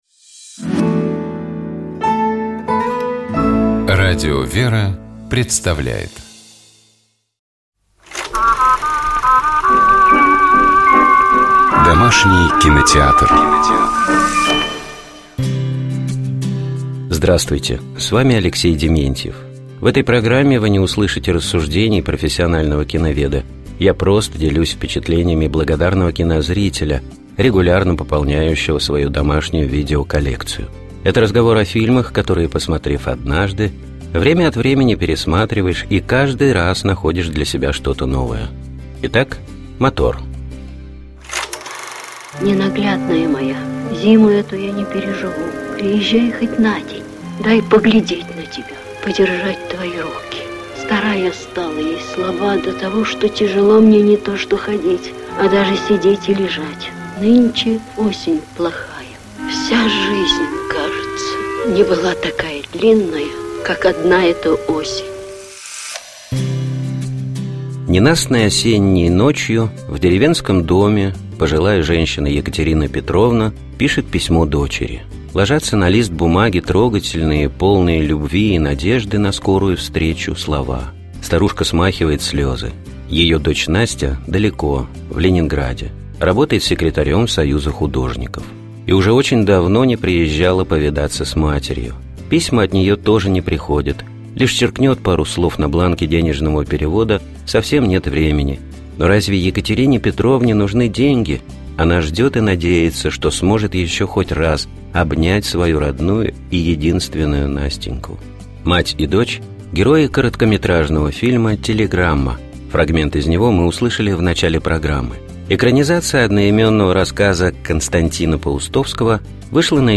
Мать и дочь — герои короткометражного фильма «Телеграмма». Фрагмент из него мы услышали в начале программы.